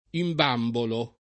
imbambolare v.; imbambolo [ imb # mbolo ]